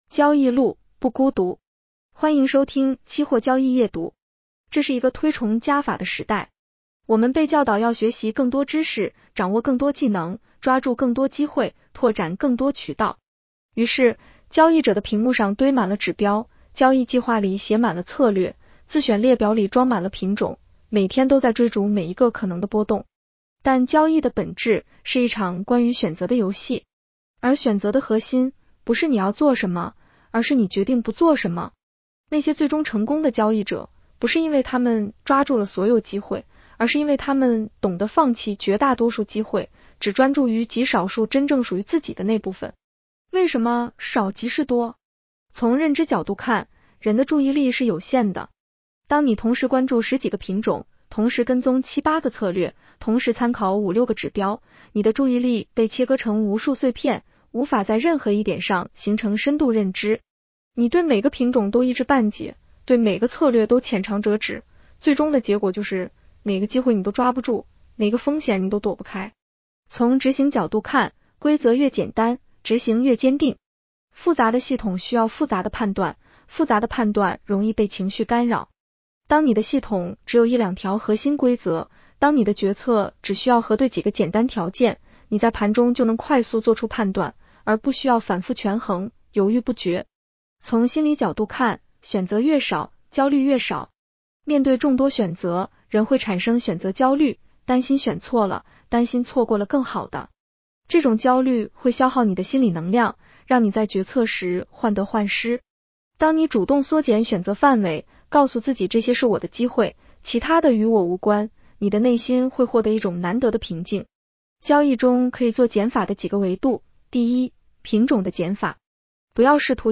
女声普通话版 下载mp3 交易路，不孤独。
（AI生成） 风险提示及免责条款：市场有风险，投资需谨慎。